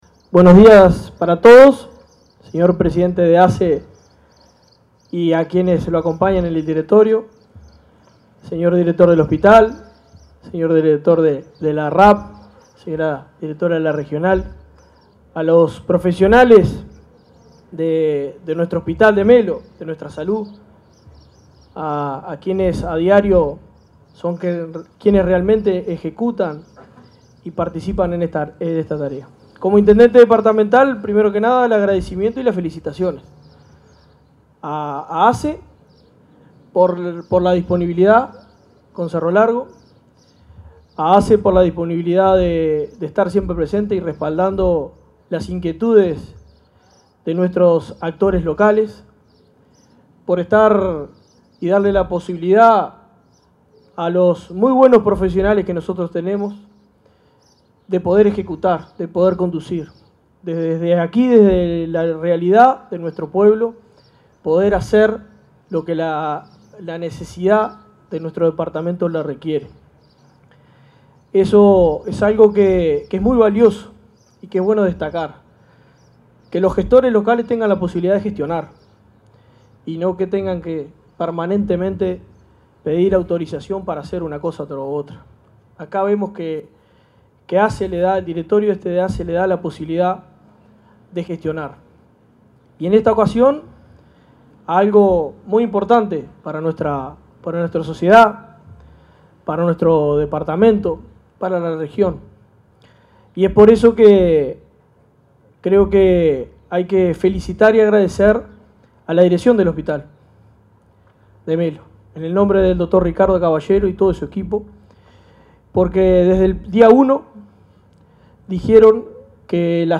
Conferencia de prensa de ASSE por inauguración de obras en el hospital de Melo